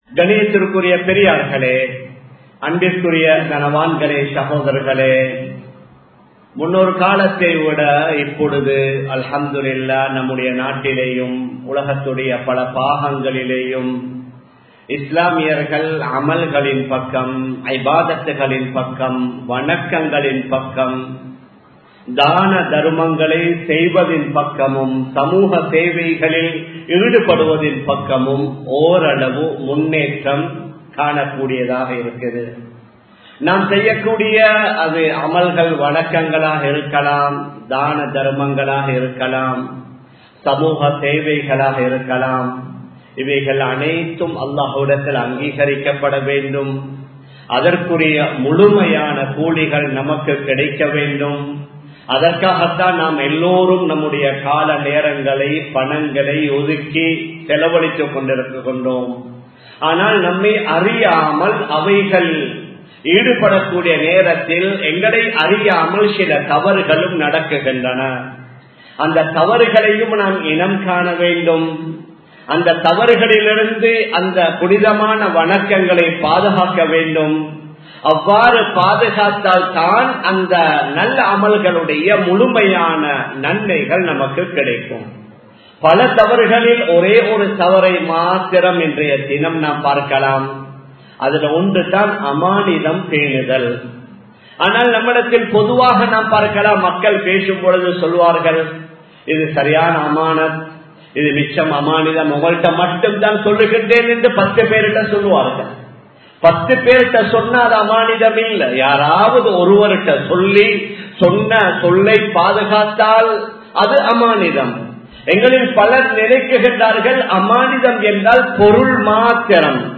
அமானிதம் பேணுவோம் | Audio Bayans | All Ceylon Muslim Youth Community | Addalaichenai
Samman Kottu Jumua Masjith (Red Masjith)